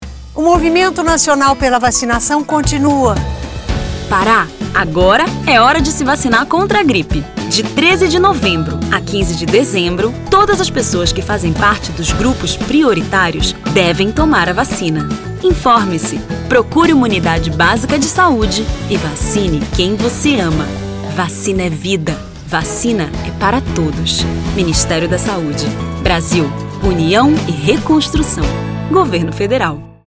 Pará: Spot - Vacinação Contra a Gripe no Pará - 30seg .mp3